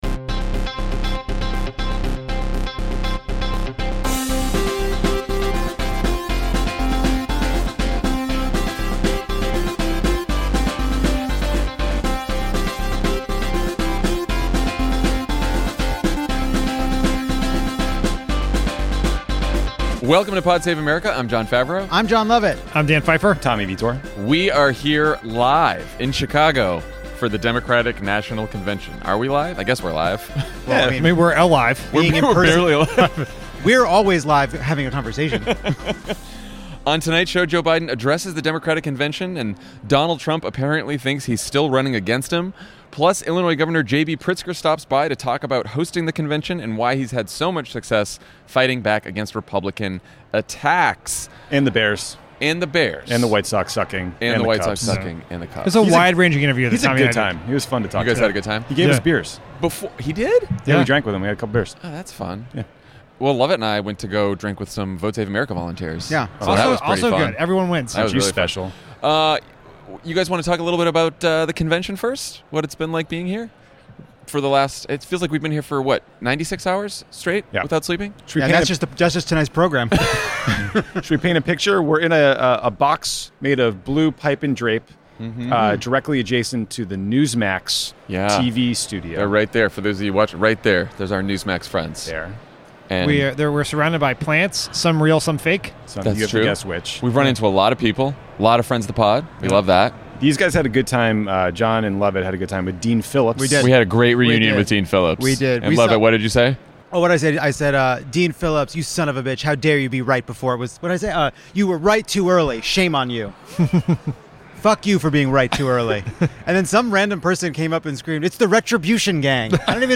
Jon, Lovett, Dan, and Tommy react to all the latest from the first day of action in Chicago as well as Trump's latest failure to stick to his script.